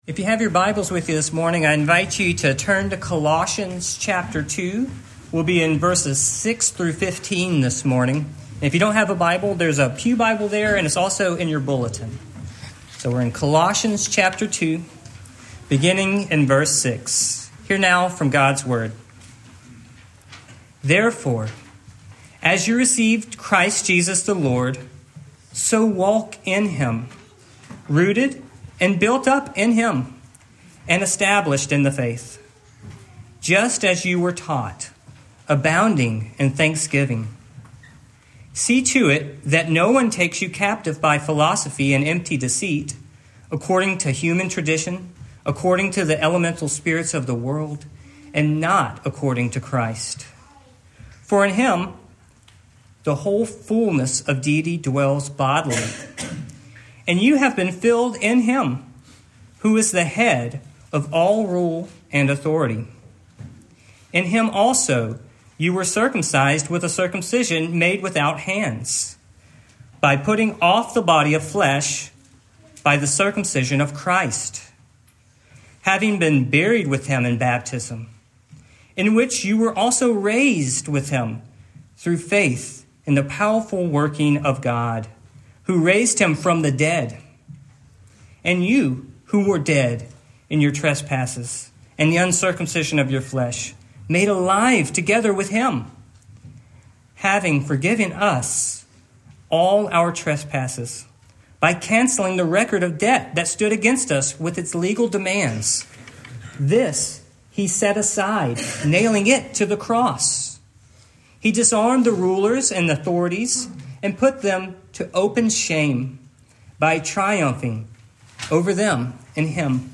Colossians 2:6-15 Service Type: Morning Our faith in Christ is anchored in the completeness of His work.